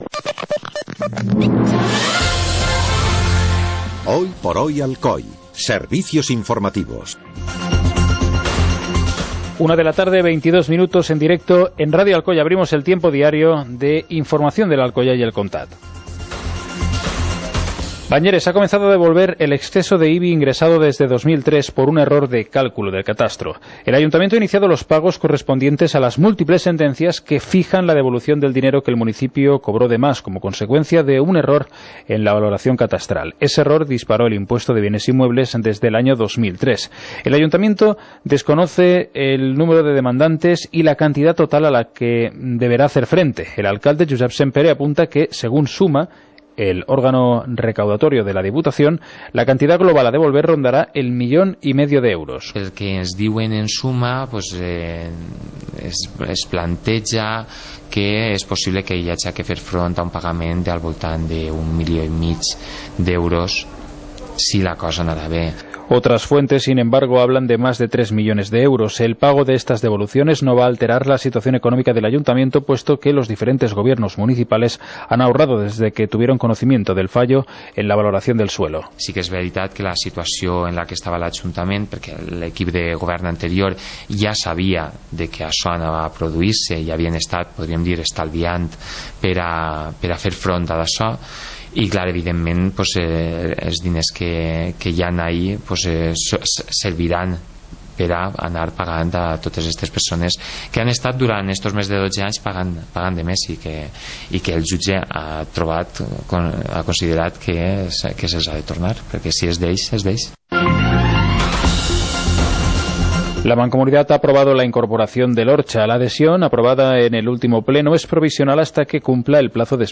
Informativo comarcal - jueves, 16 de junio de 2016